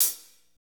Index of /90_sSampleCDs/Northstar - Drumscapes Roland/DRM_Slow Shuffle/KIT_S_S Kit 1 x
HAT S S H0HR.wav